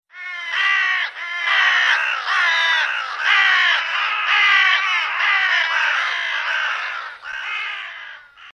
Звуки ворон, воронов
На этой странице собраны разнообразные звуки ворон и воронов: от одиночных карканий до хоровых перекличек.